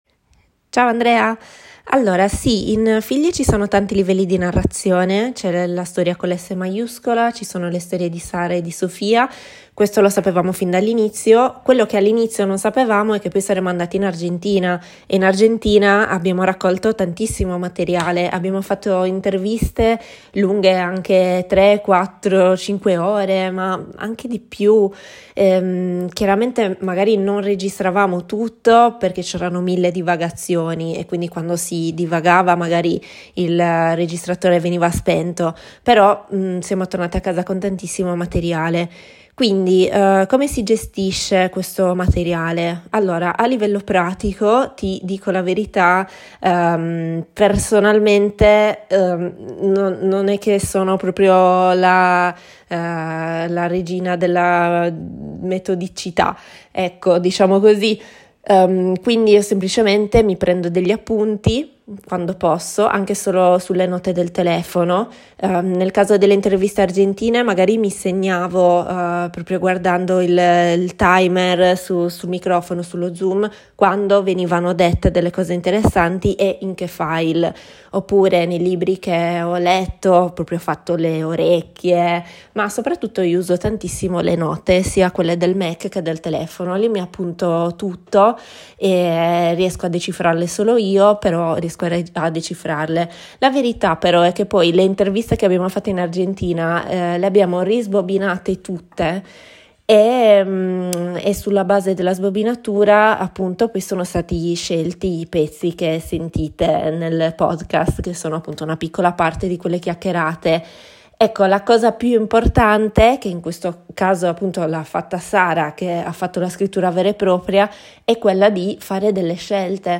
audio-intervista